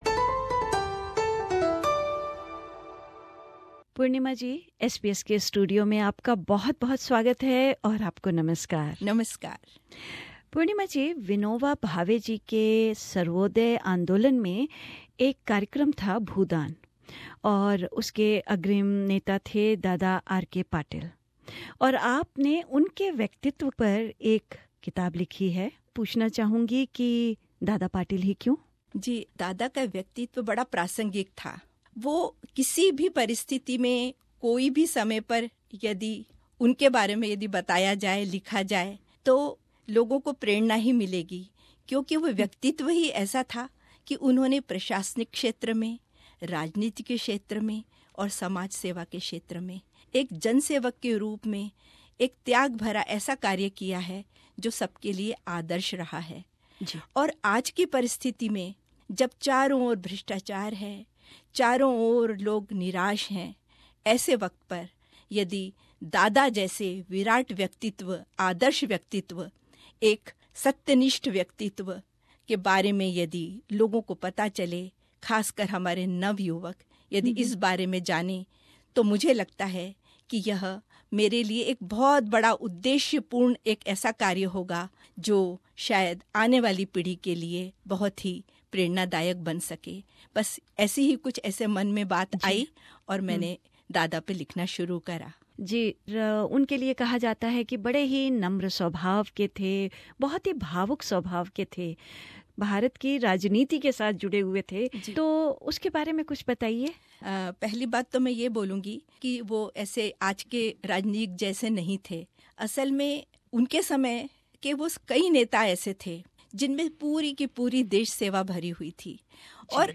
बातचीत ...